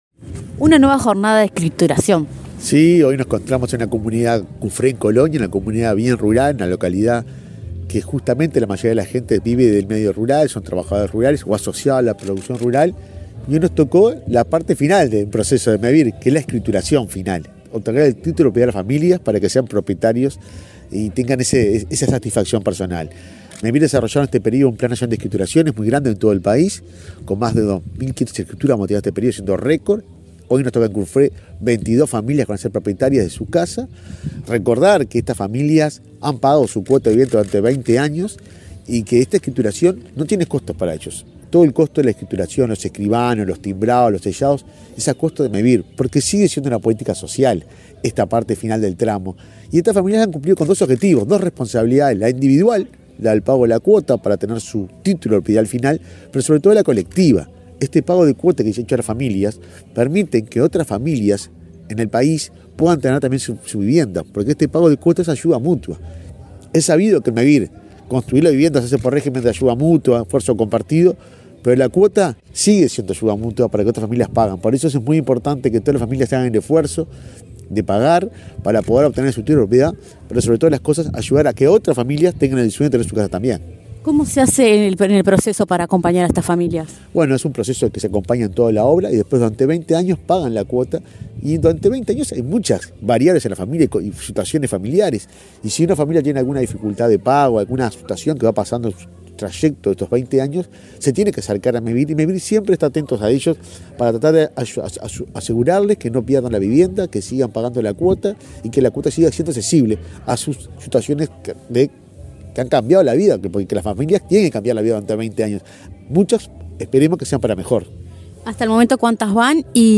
Entrevista al presidente de Mevir, Juan Pablo Delgado
El presidente de Mevir, Juan Pablo Delgado, dialogó con Comunicación Presidencial en Colonia, antes de participar en el acto de escrituración de